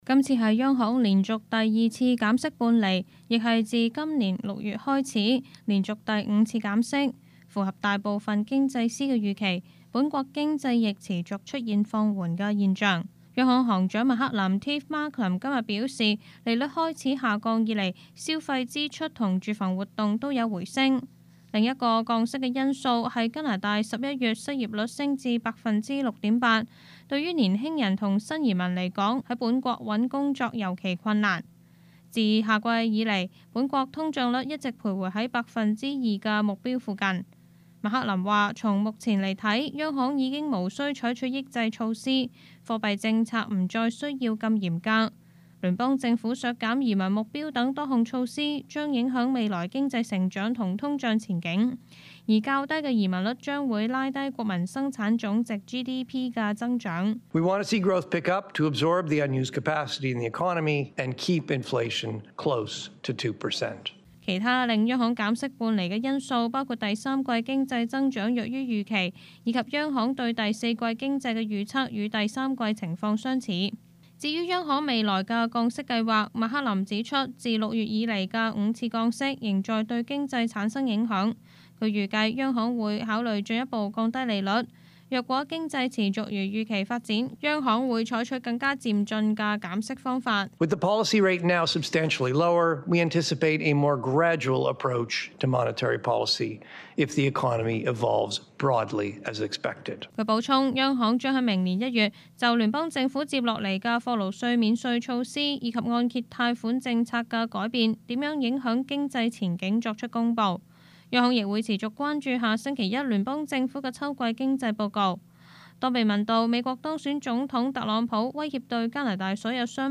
news_clip_21670.mp3